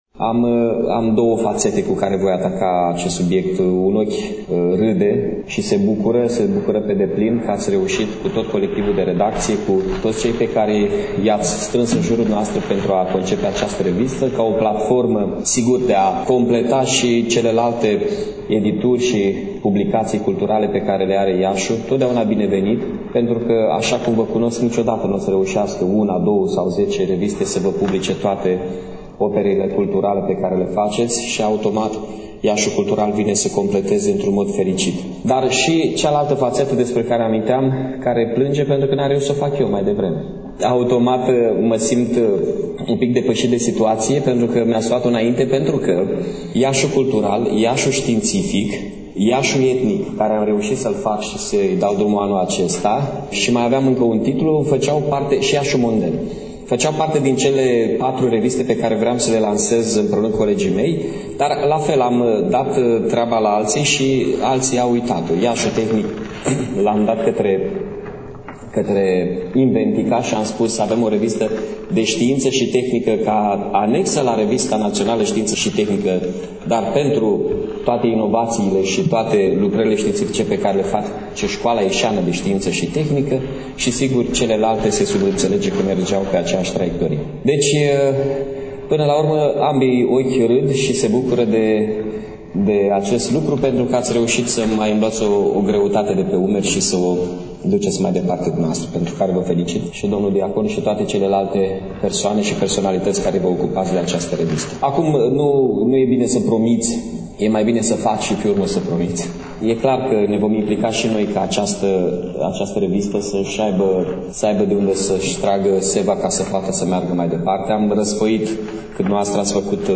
De la eveniment, în următoarele minute, ascultăm discursul lui Mihai Chirica, primarul Municipiului Iași, cel care ne creionează o imagine a acestei inițiative editoriale.